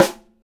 SNR 4-WAY 03.wav